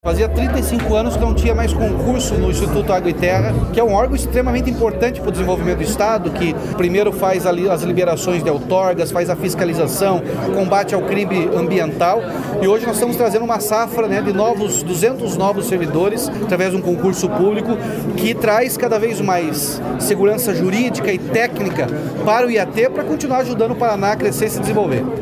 Sonora do governador Ratinho Junior sobre a posse de 200 novos servidores no Instituto Água e Terra (IAT)